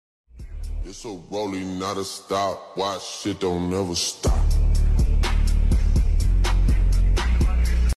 A nice Green BMW M2 sound effects free download
A nice Green BMW M2 at BMW Bristol!